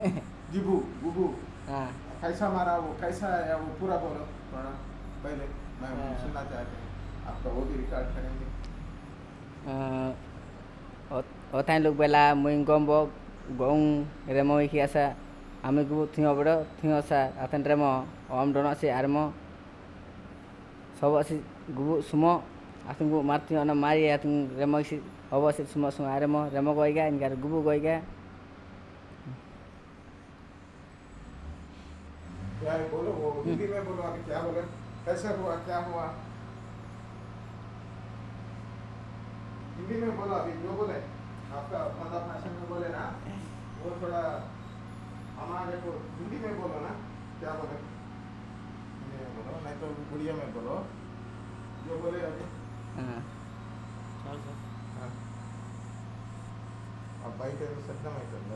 Retelling of a story in Bondo
NotesThis is a Retelling of a story in Bondo